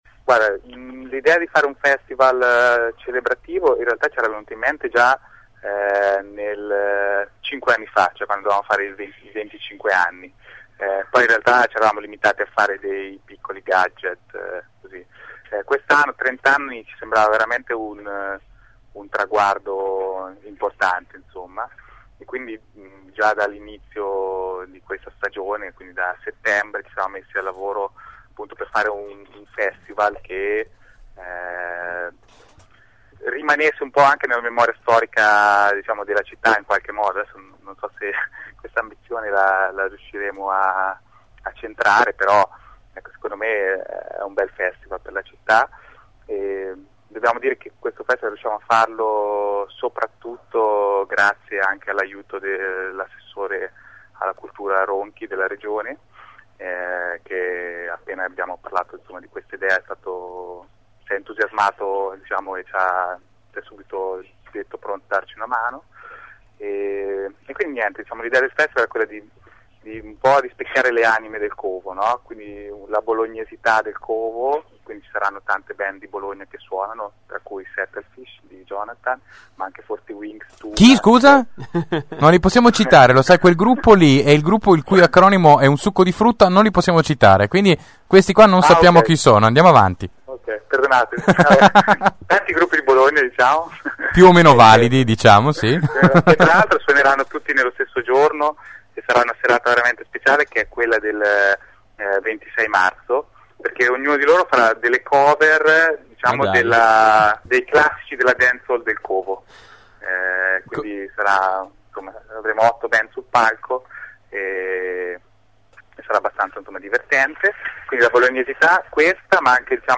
covo-club-30-intervista.mp3